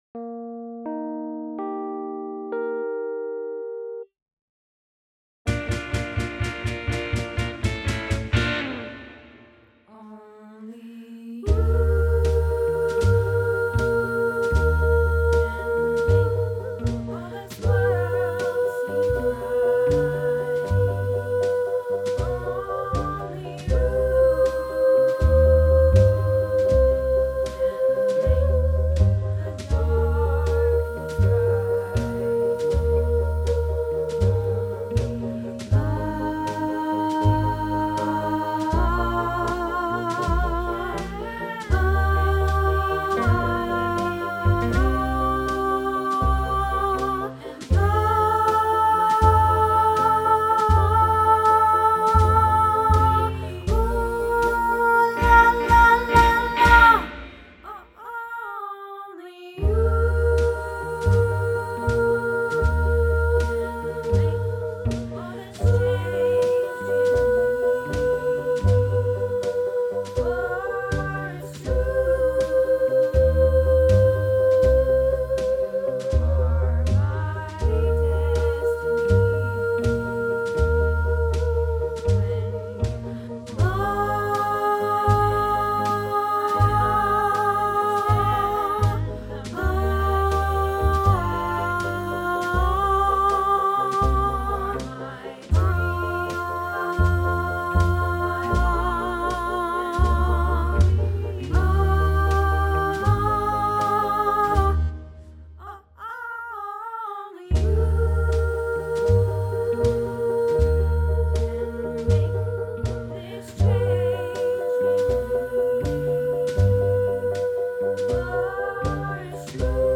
Only You - Sop